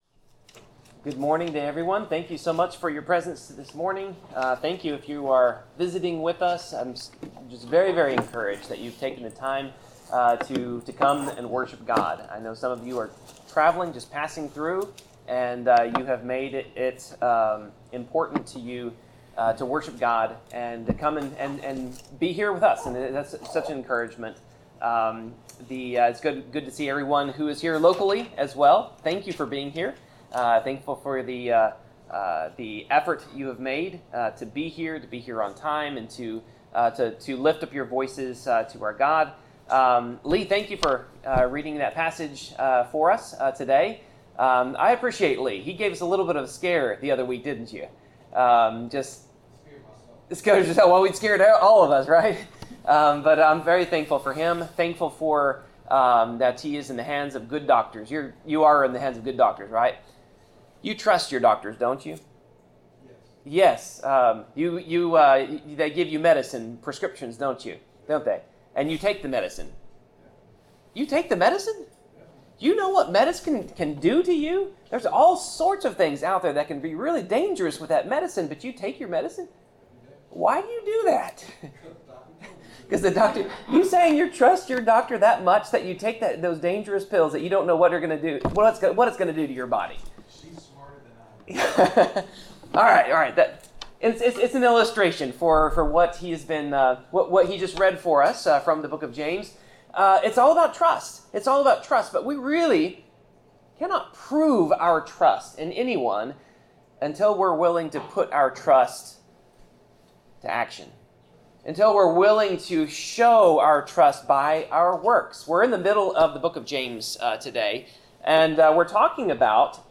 Passage: James 2:14-26 Service Type: Sermon